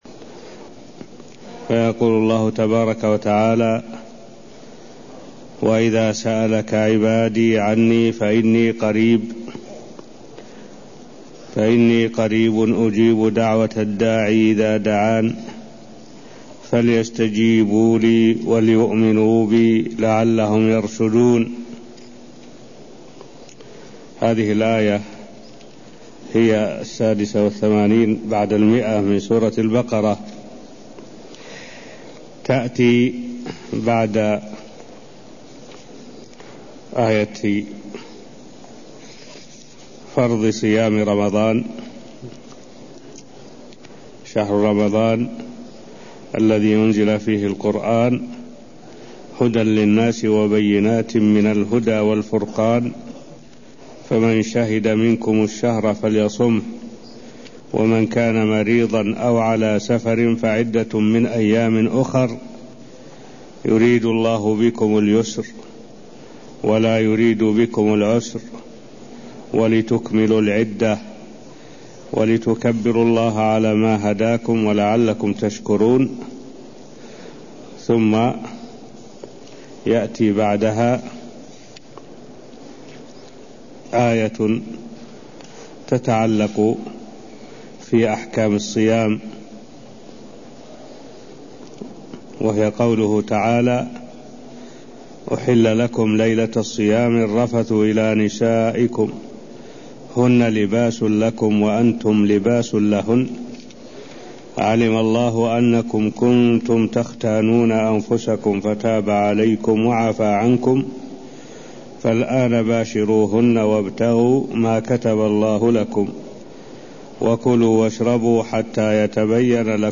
المكان: المسجد النبوي الشيخ: معالي الشيخ الدكتور صالح بن عبد الله العبود معالي الشيخ الدكتور صالح بن عبد الله العبود تفسير الآيات186ـ187 من سورة البقرة (0090)